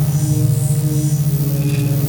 gauss_precision_charge_idle.wav